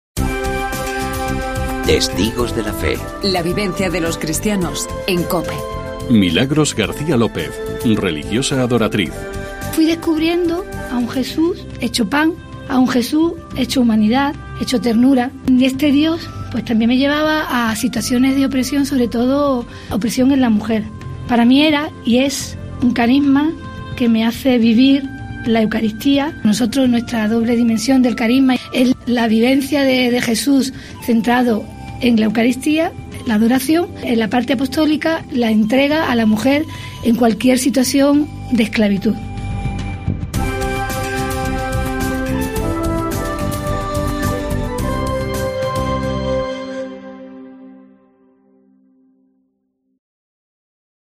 Escuchamos el testimonio de la Adoratriz